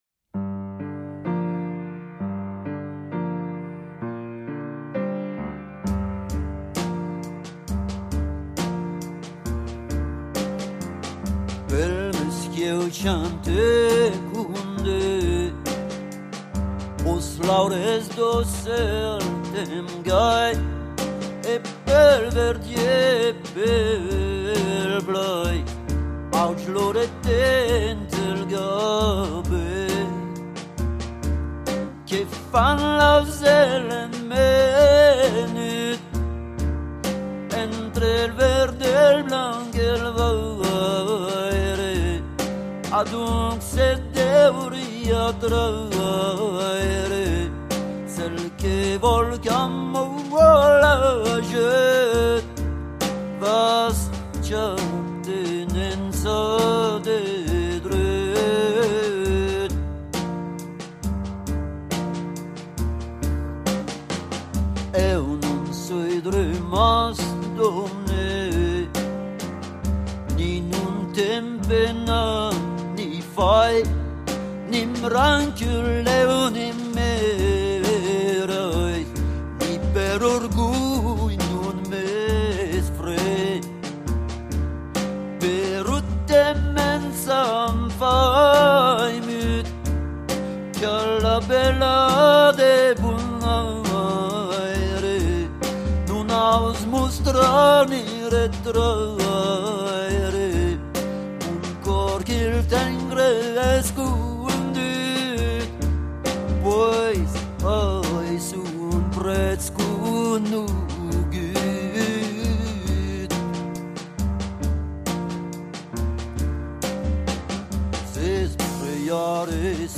cansos dels trobadors /